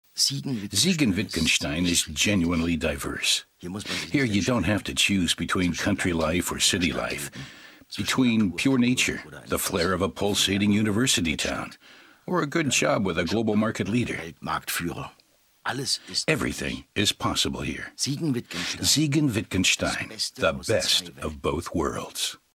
Zwei weitere Sprecher unterstützen ihn bei den Interviewpassagen des Films.